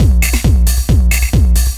DS 135-BPM D6.wav